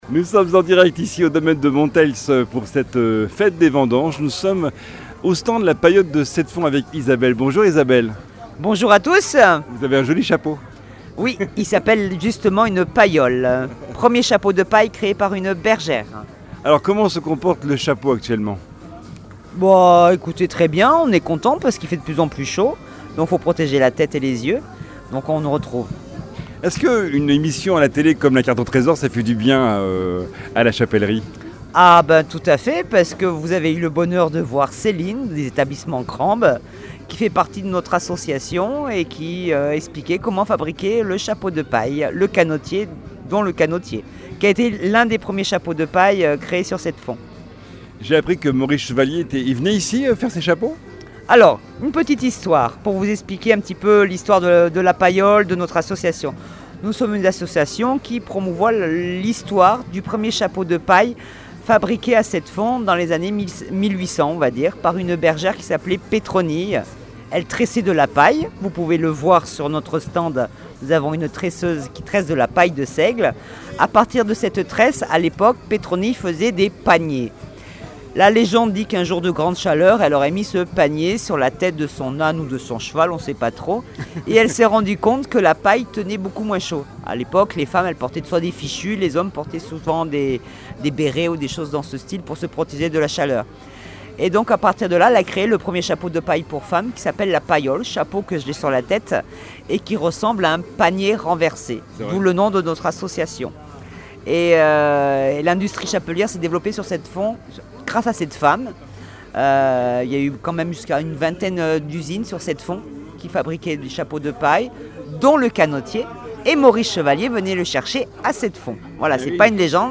Invité(s)
Présenté par